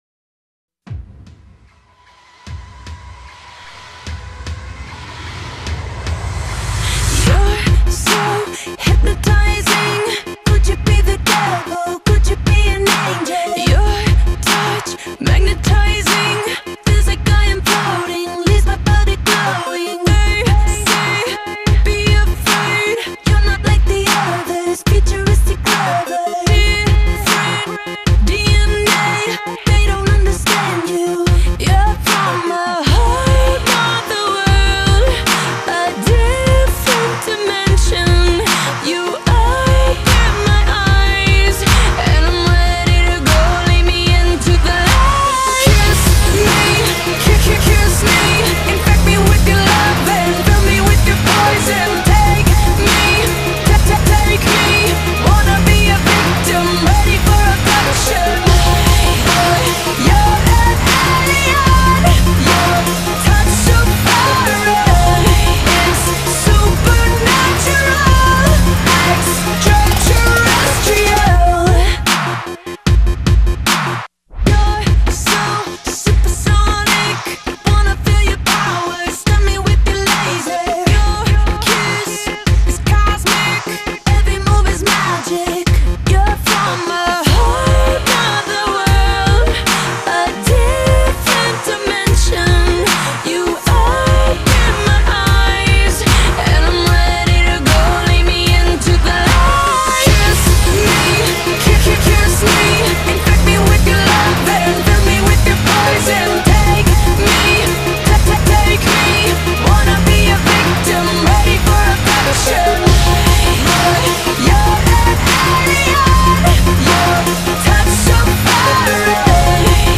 Electro‑Pop / Trap‑inspired